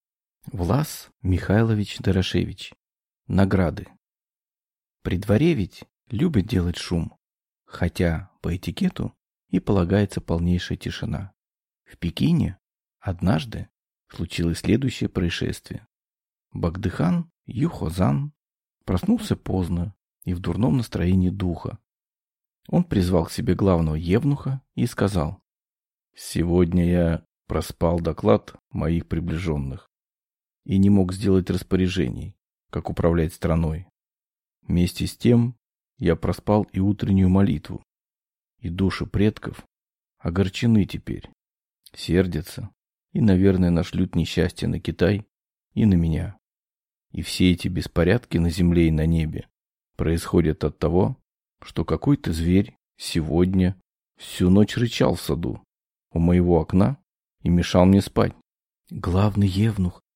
Aудиокнига Награды